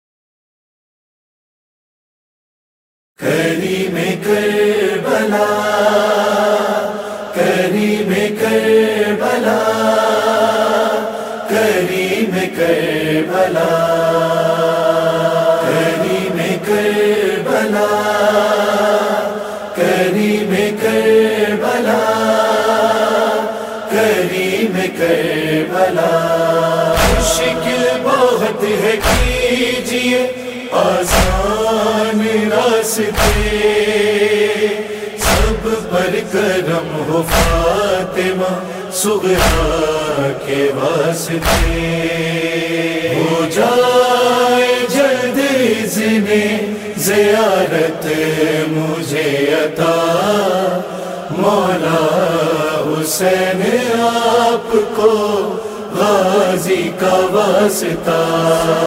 Noha